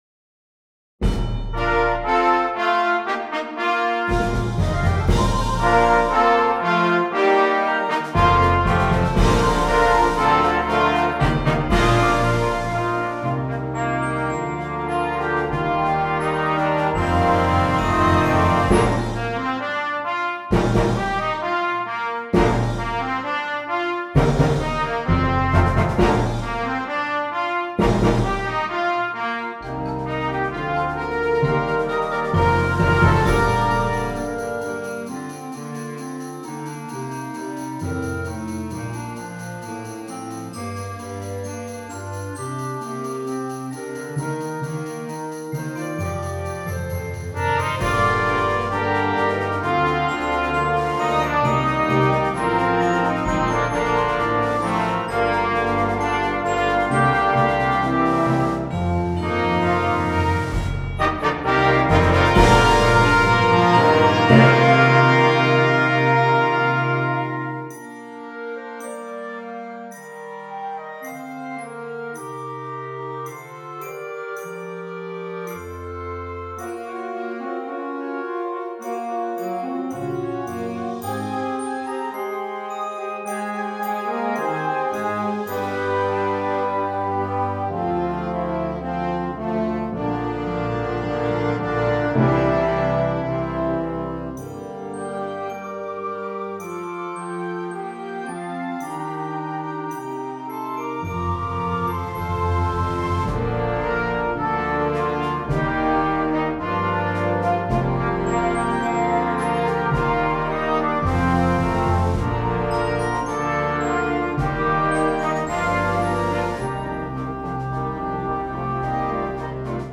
Concert Band
is a big-sounding piece for young band